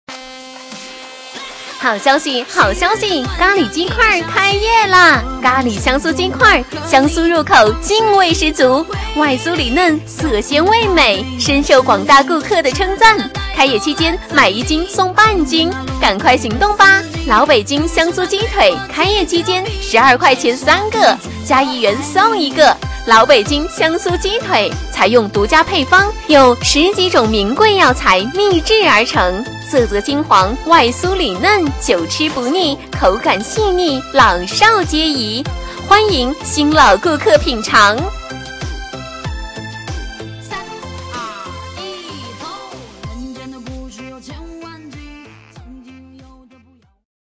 【女58号促销】咖喱鸡块